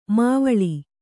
♪ m`vaḷi